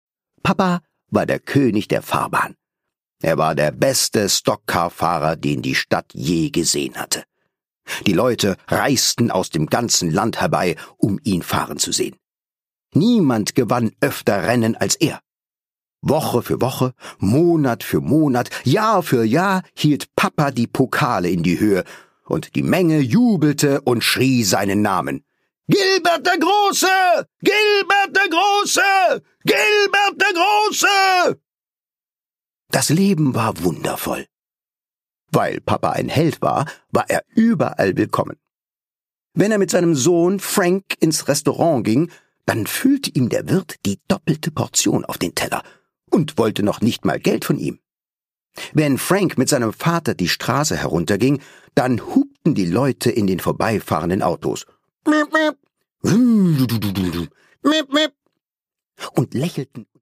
Produkttyp: Hörbuch-Download
Gelesen von: Thomas Nicolai
Er palavert, brummt und ballert, dass die Fetzen fliegen!